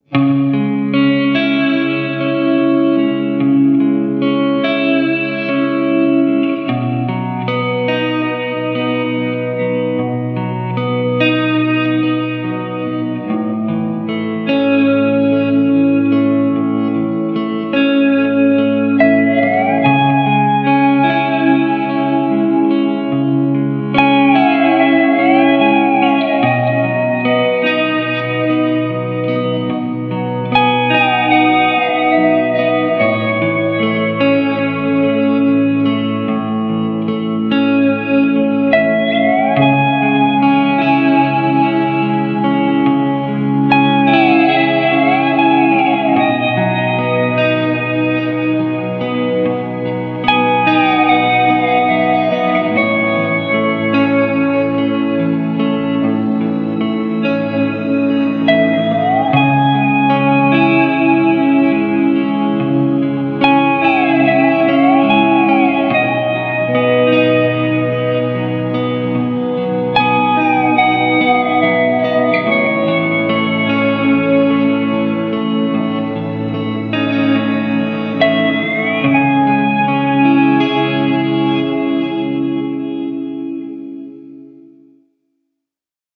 Sustained six string mutations.
Sad melancholic guitar theme.